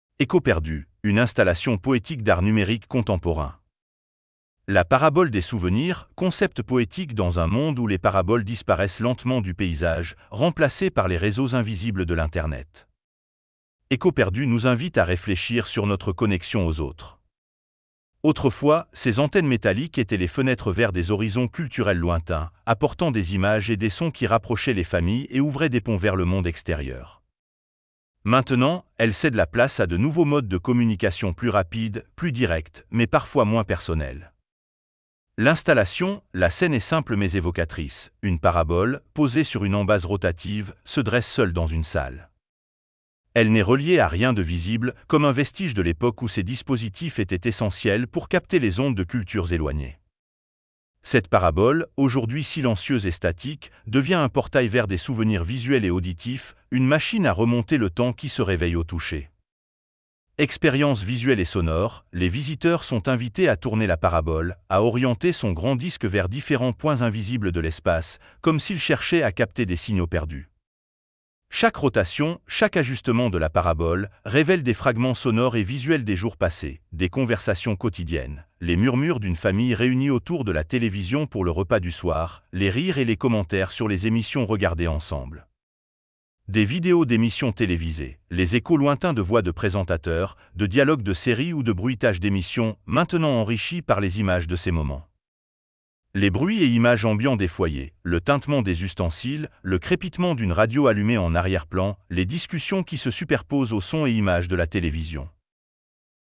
• Des Conversations Quotidiennes : Les murmures d’une famille réunie autour de la télévision pour le repas du soir, les rires et les commentaires sur les émissions regardées ensemble.
• Des Vidéos d’Émissions Télévisées : Les échos lointains de voix de présentateurs, de dialogues de séries ou de bruitages d’émissions, maintenant enrichis par les images de ces moments.
• Les Bruits et Images Ambiants des Foyers : Le tintement des ustensiles, le crépitement d’une radio allumée en arrière-plan, les discussions qui se superposent aux sons et images de la télévision.